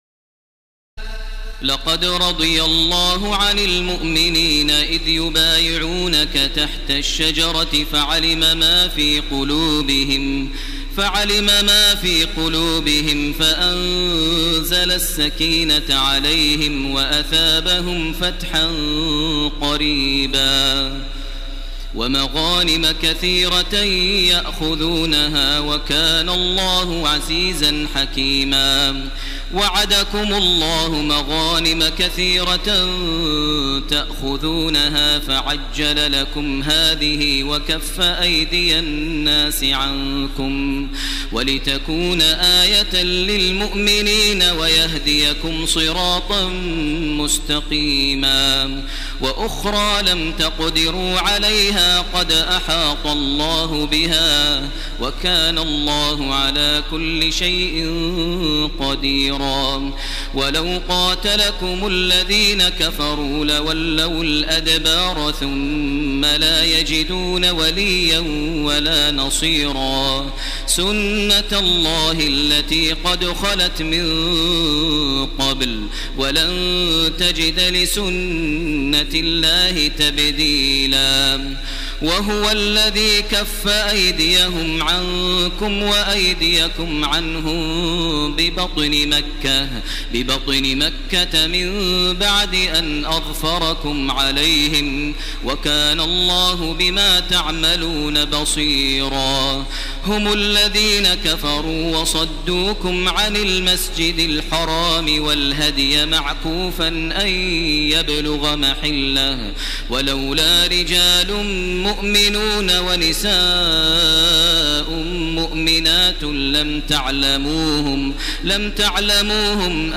تراويح ليلة 25 رمضان 1430هـ من سور الفتح (18-29) الحجرات و ق و الذاريات Taraweeh 25 st night Ramadan 1430H from Surah Al-Fath and Al-Hujuraat and Qaaf and Adh-Dhaariyat > تراويح الحرم المكي عام 1430 🕋 > التراويح - تلاوات الحرمين